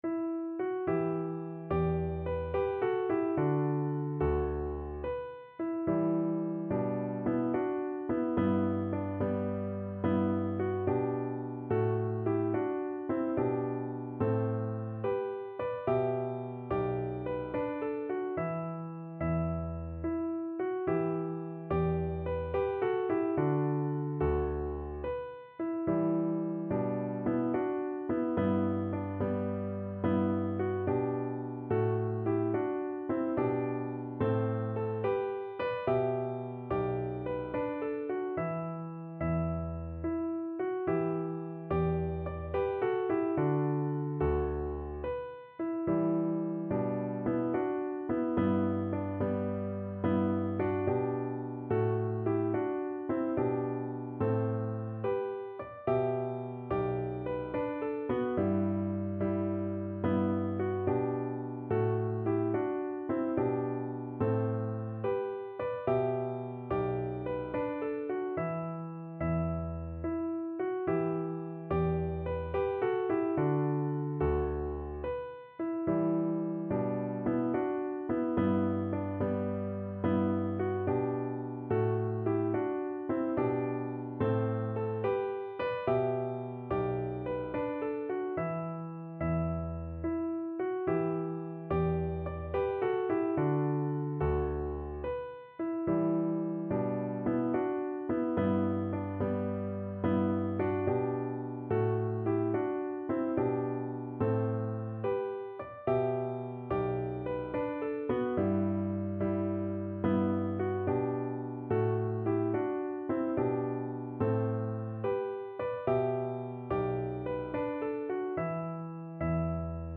Play (or use space bar on your keyboard) Pause Music Playalong - Piano Accompaniment Playalong Band Accompaniment not yet available transpose reset tempo print settings full screen
9/8 (View more 9/8 Music)
Gently .=c.72
E minor (Sounding Pitch) (View more E minor Music for Voice )